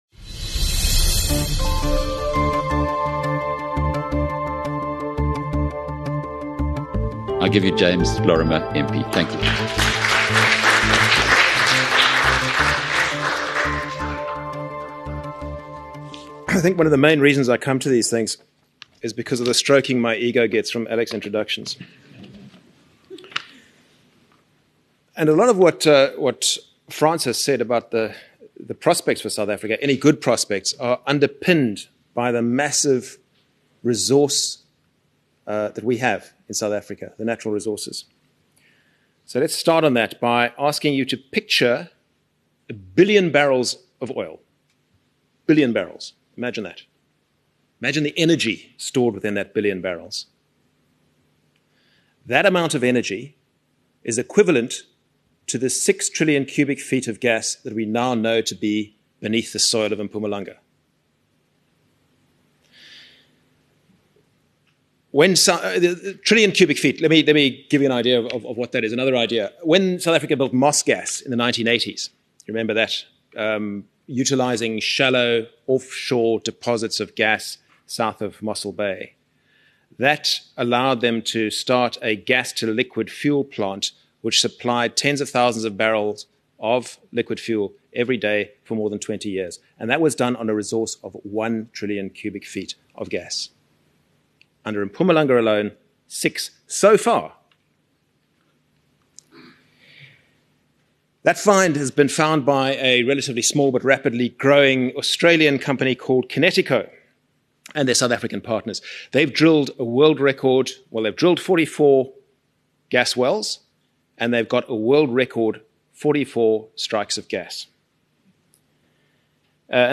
In a riveting keynote address at the BizNews Conference in London, DA Shadow Minister of Mining, James Lorimer MP, unveiled groundbreaking discoveries reshaping the nation's energy landscape. With the revelation of vast gas and oil reservoirs beneath Mpumalanga's soil and offshore along the Orange Basin, South Africa stands at the cusp of an economic revolution. Lorimer's detailed exploration of these finds, from Connecticut's pioneering gas-to-power ventures to Namibia's offshore bonanza, painted a picture of transformative potential.